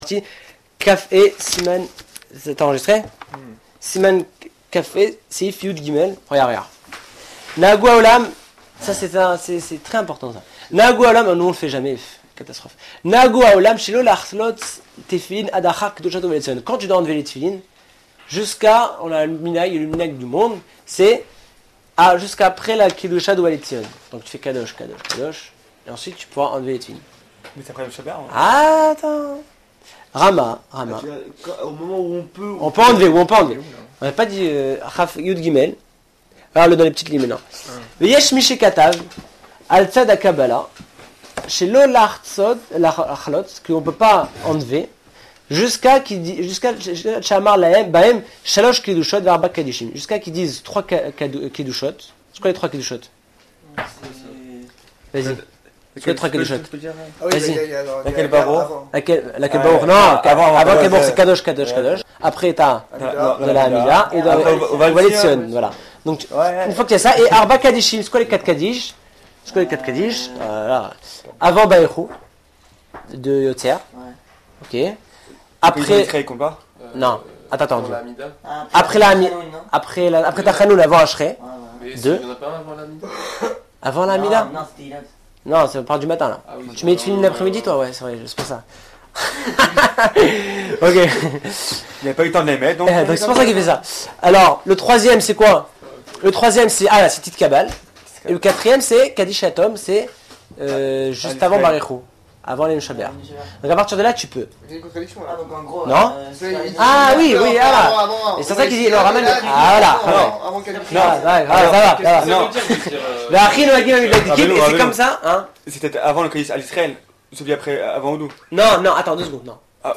Cinquième Cours sur les téfiline.